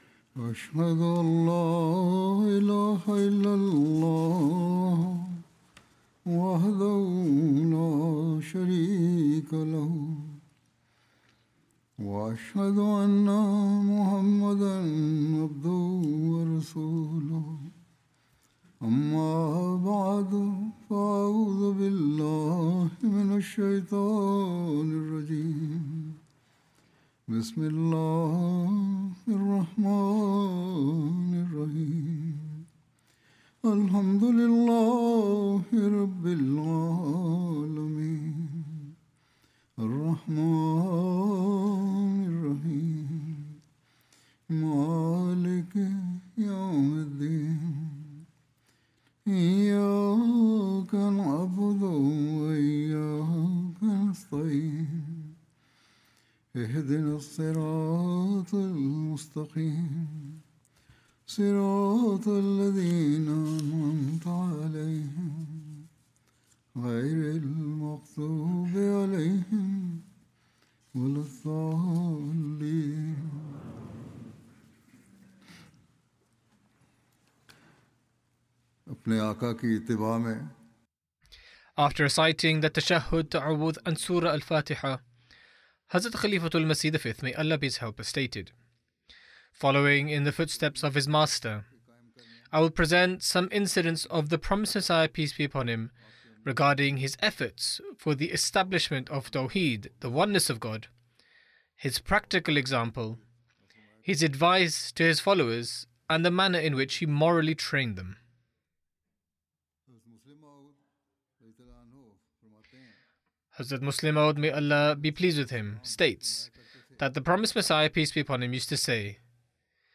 English translation of Friday Sermon (audio)